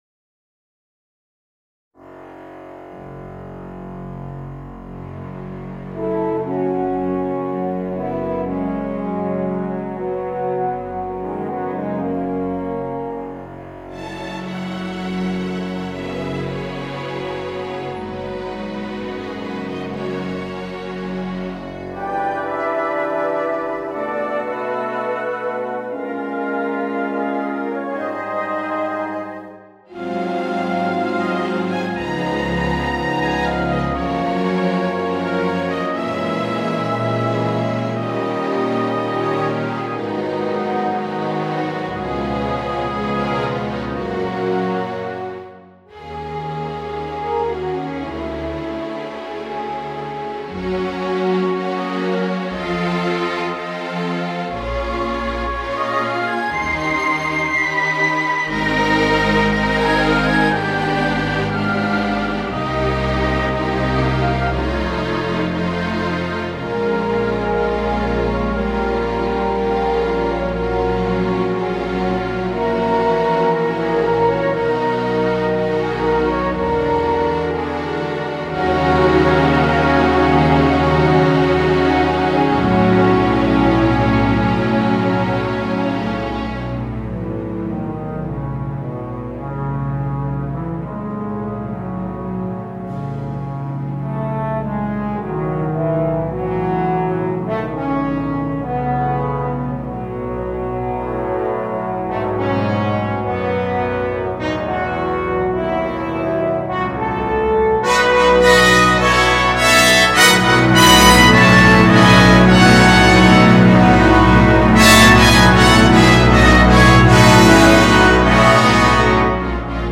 Alphorn in Gb & Orchestra
Sinfonieorchester PDF